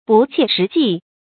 不切实际 bù qiē shí jì
不切实际发音